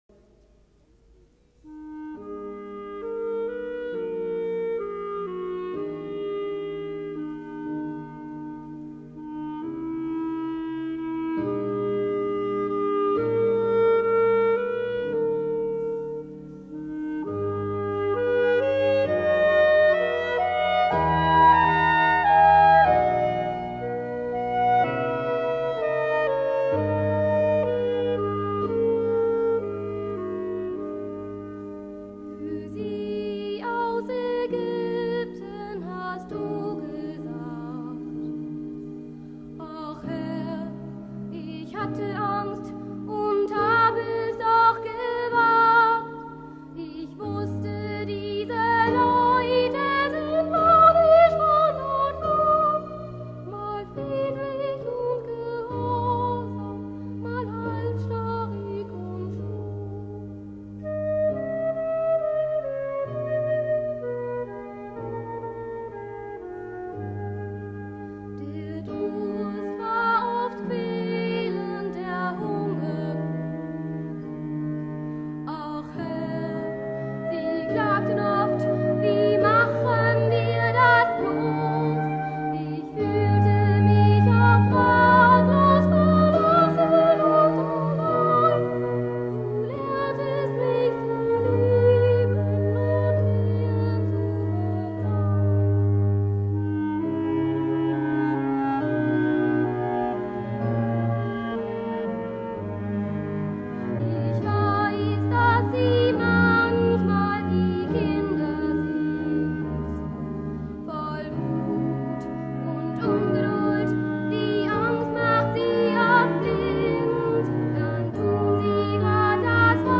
Das Kindermusical basiert auf
Moseslied, Marienkirche Minden 1996
Instrumente: Flöte, Violine, Klarinette, Klavier